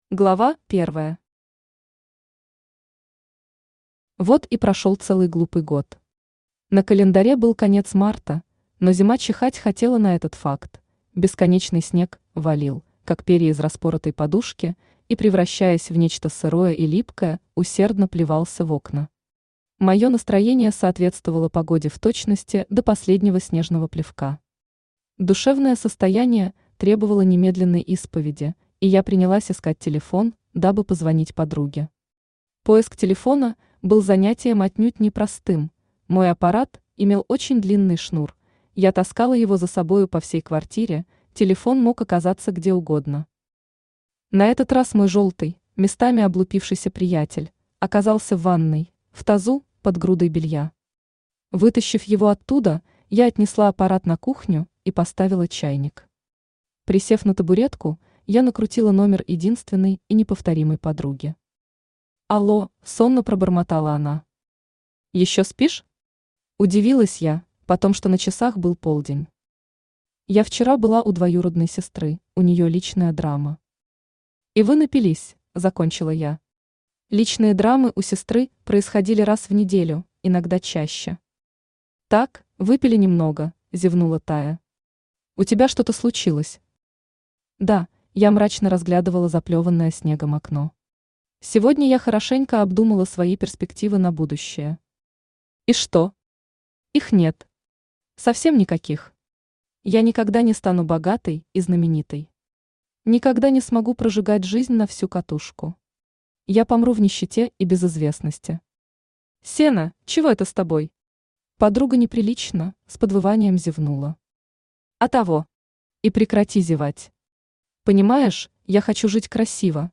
Аудиокнига Суп из птичьих гнезд | Библиотека аудиокниг
Aудиокнига Суп из птичьих гнезд Автор Галина Полынская Читает аудиокнигу Авточтец ЛитРес.